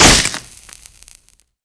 rifle_hit_wood2.wav